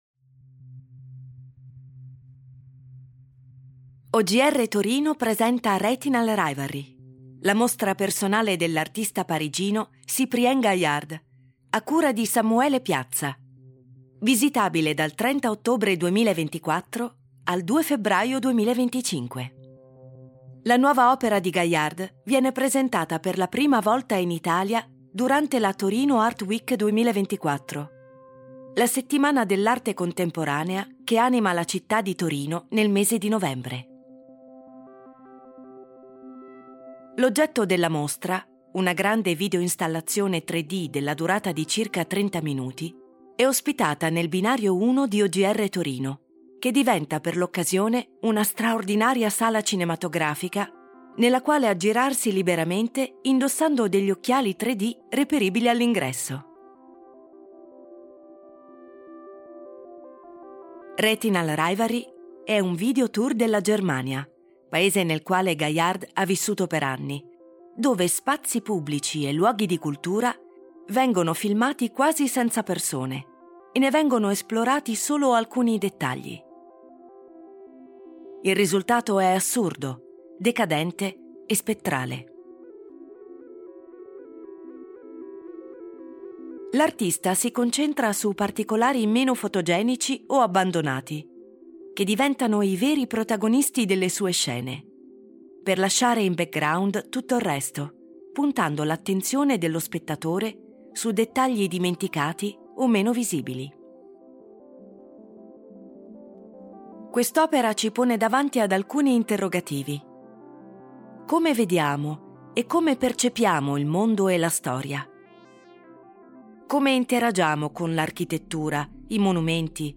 musiche e sound design di NoMad Studio